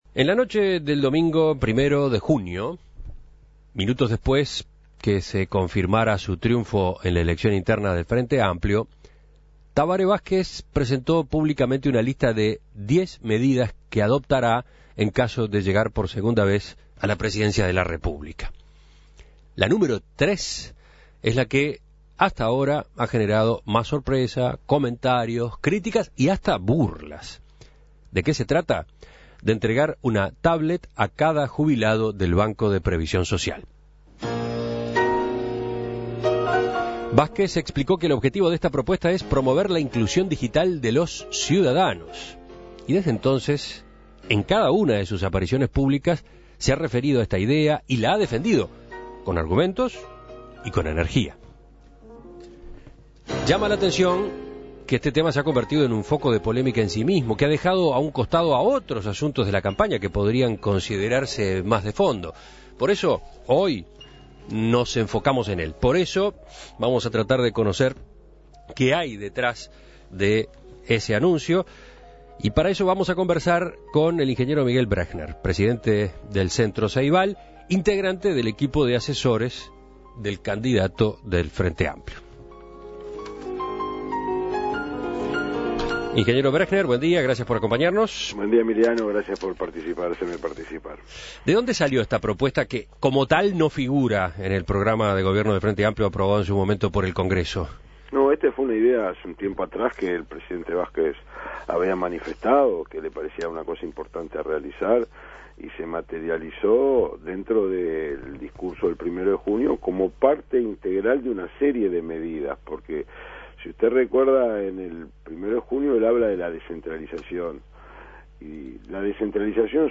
Para comprender mejor este anuncio, En Perspectiva entrevistó al ingeniero Miguel Brechner, presidente del Centro Ceibal e integrante del equipo de asesores del candidato oficinista.